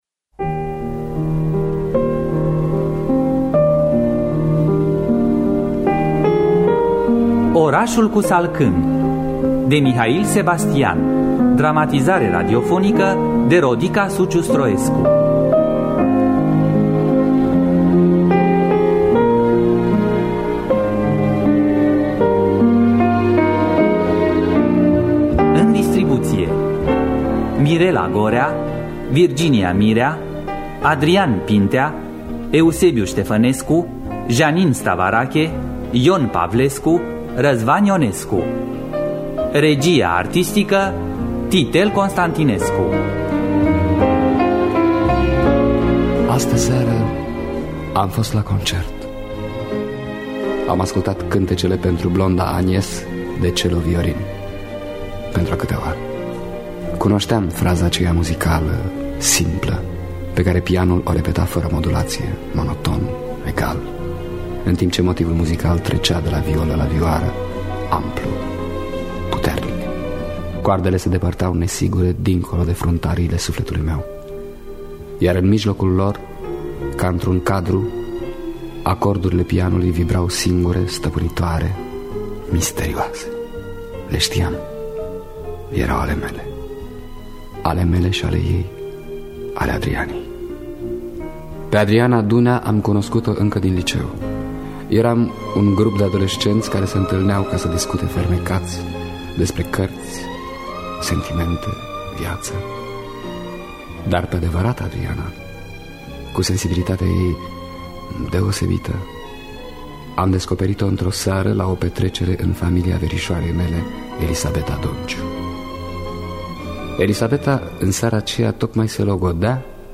Dramatizare radiofonică
Înregistrare din anul 1989.